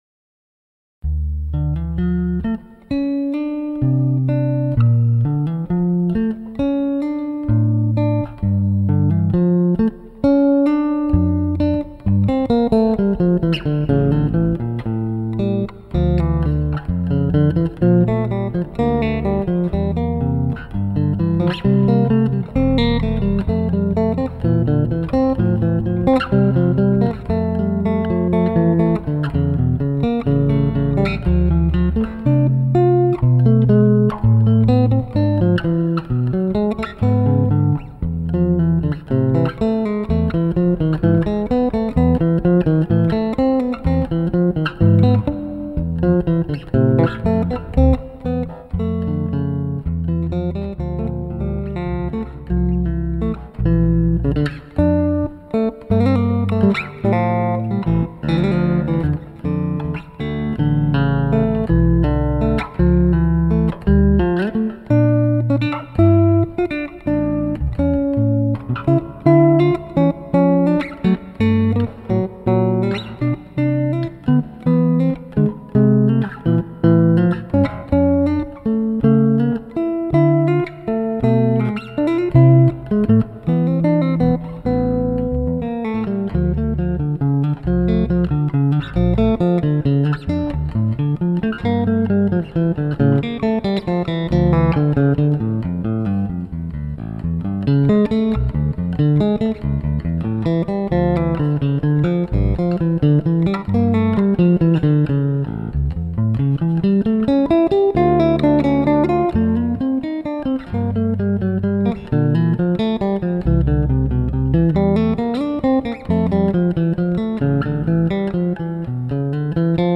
trascrizione e adattamento per basso elettrico